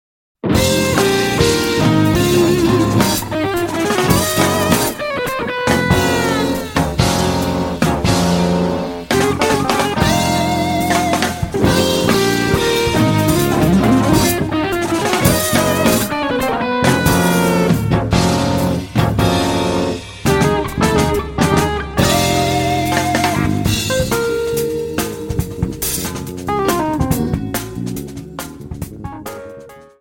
スタンダード・ナンバーをハードなアレンジ、かつアグレッシブな演奏で収録した作品。
よりスリリングなフュージョン・ナンバーに仕上げている。